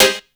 35SYNT02  -L.wav